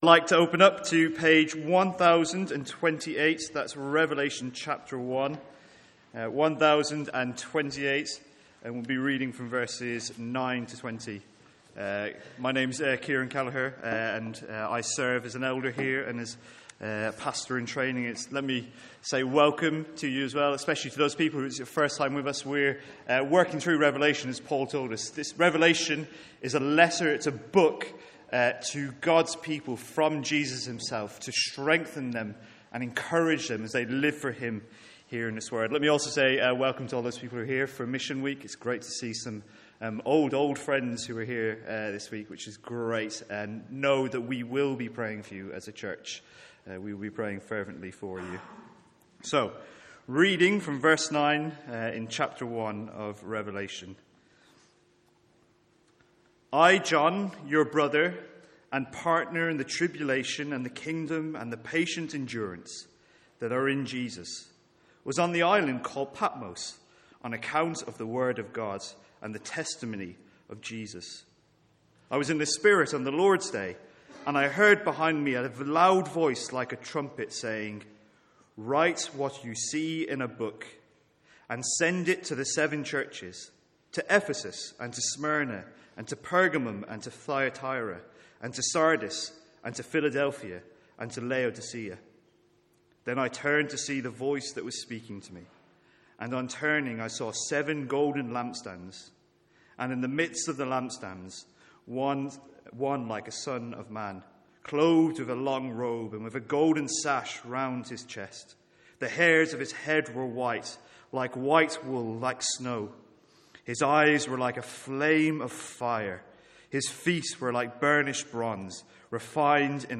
Sermons | St Andrews Free Church
From our evening series in the book of Revelation.